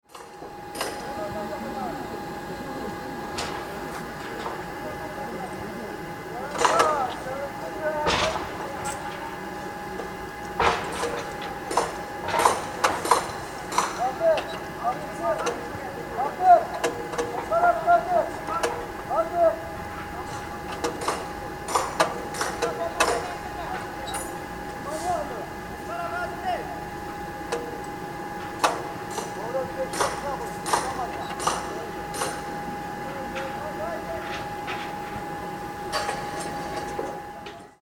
Active Construction Site Sound Effect
Capture the organic atmosphere of a busy building zone. This authentic sound recording features rhythmic metal-on-metal striking and worker communication.
Active-construction-site-sound-effect.mp3